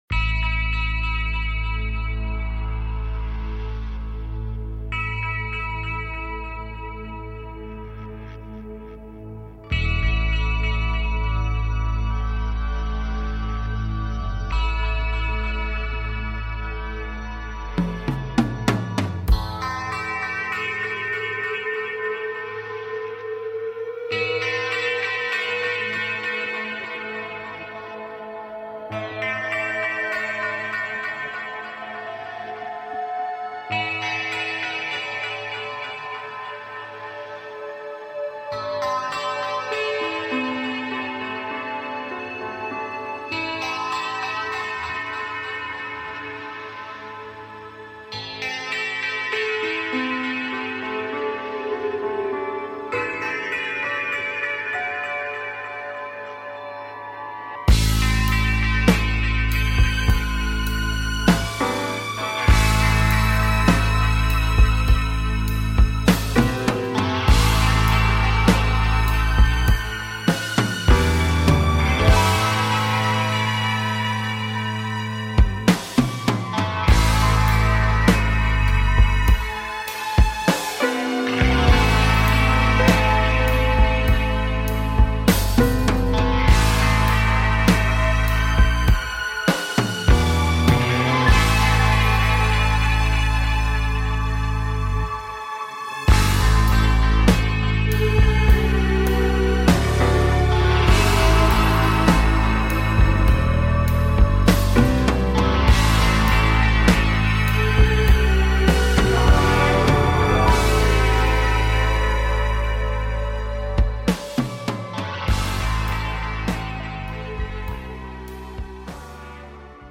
Talk Show Episode, Audio Podcast
Reviewing listener projects and answering listener calls